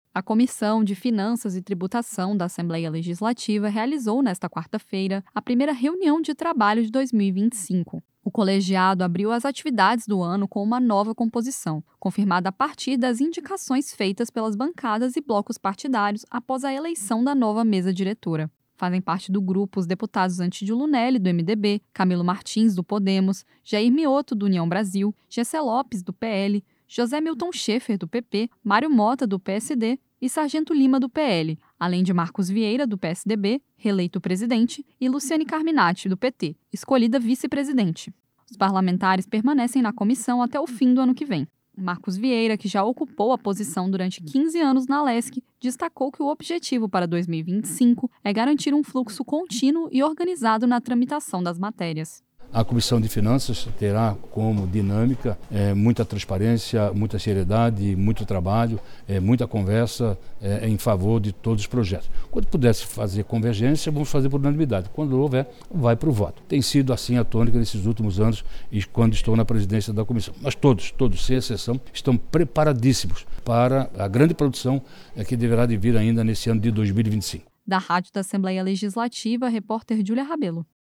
Entrevista com:
- deputado Marcos Vieira (PSDB), presidente da Comissão de Finanças e Tributação da Assembleia Legislativa.